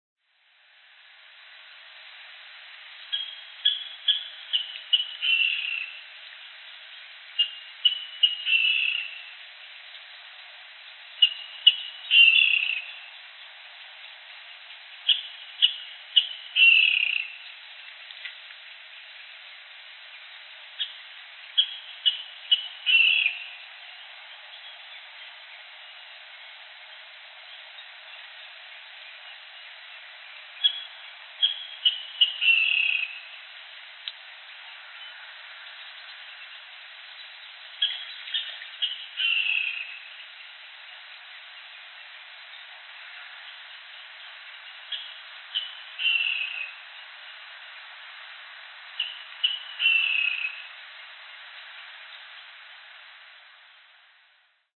Avvikande vattenrall
Vattenrall med ovanligt läte
Fågeln spelades in på band.
Spelet inleddes med 3-4 tärnlika "ki", som också skulle kunna liknas vid en vattenrall i högt läge. Ibland förnamms en acceleration likt den hos vattenrall, samtidigt som tonhöjden sjönk ned mot den avslutande delen. Den efterföljande delen av spelet bestod i en likaledes tärnlik (anfallande silvertärna), om årta påminnande strof (se sonogram).